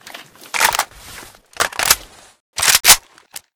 ak74_reload_empty.ogg